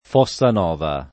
[ f qSS an 0 va ]